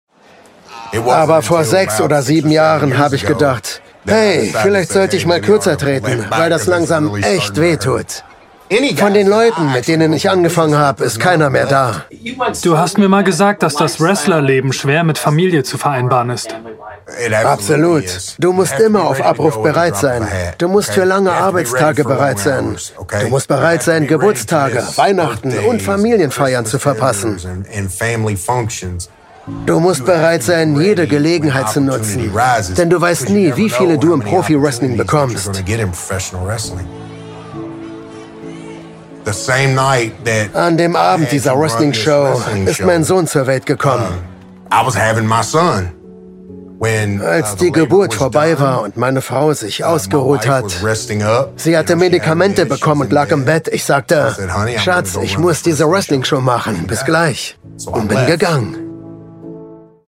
markant, sehr variabel
Mittel plus (35-65)
Doku